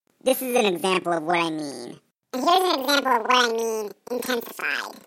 When I use the change pitch function on Audacity, the audio comes off as stuttery and echoey.